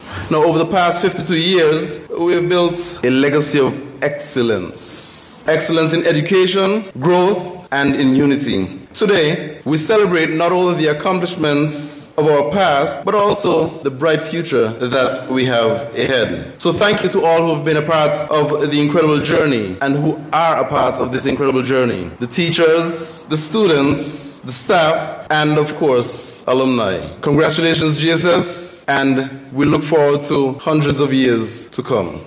The Gingerland Secondary School opened its doors to many students and teachers on January 24th, 1973, and celebrated 52 years last Friday with a church service which took place at the Gingerland Methodist Church.